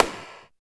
45 SNARE.wav